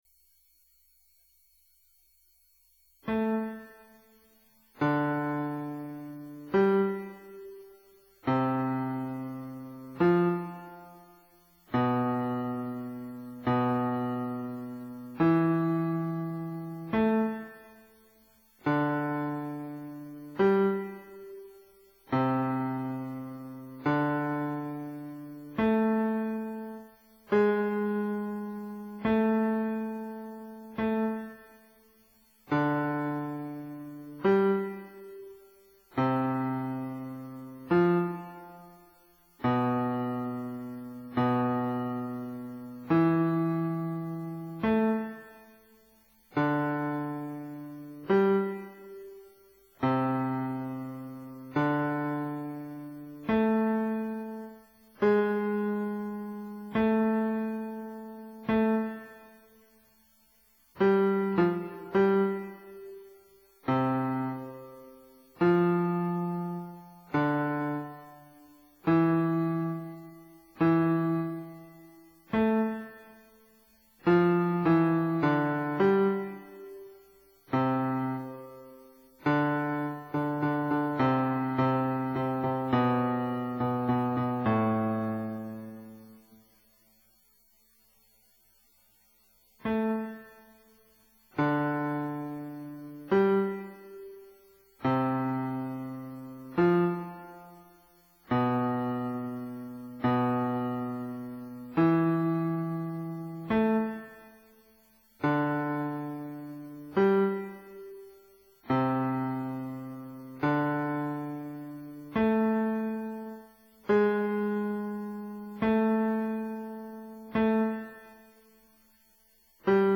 basshimlen.mp3